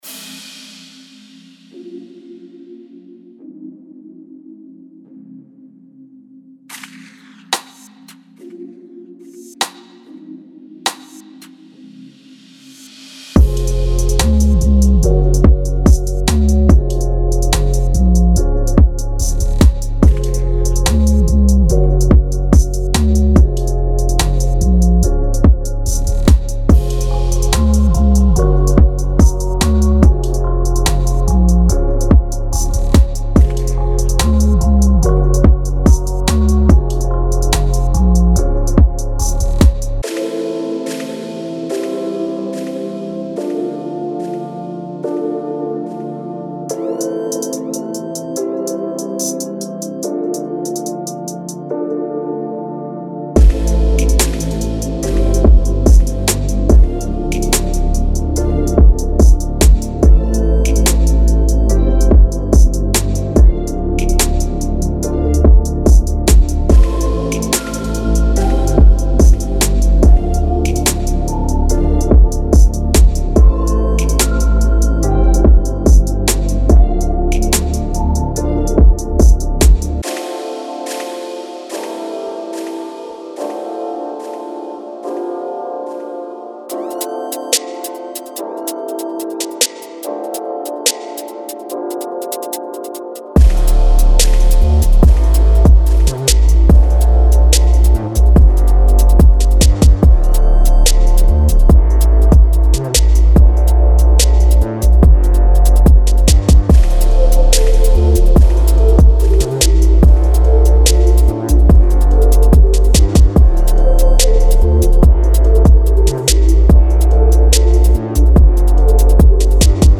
Genre:Drill
私たちはドリルを、非常に興味深いR&Bとの組み合わせによって次のレベルへと引き上げます。
デモサウンドはコチラ↓